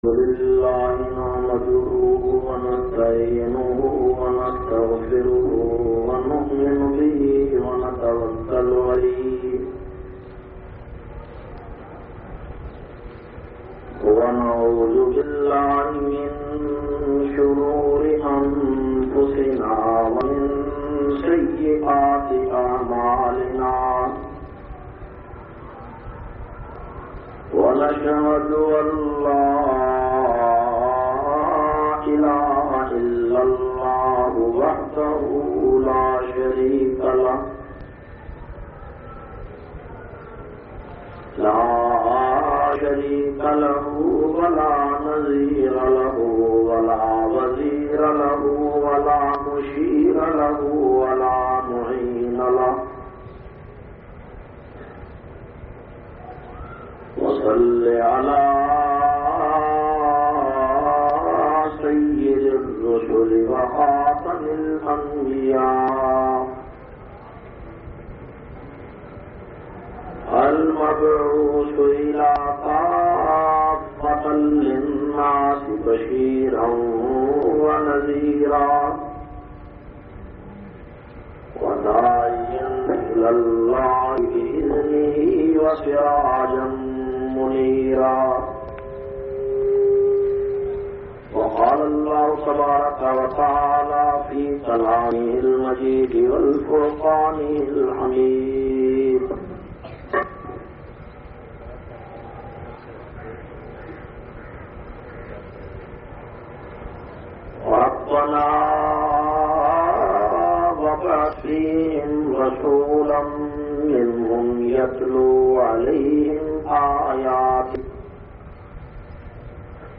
399- Seerat un Nabi jumma khittab Jamia Masjid Muhammadia Samandri Faisalabad.mp3